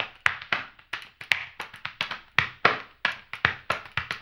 HAMBONE 11-L.wav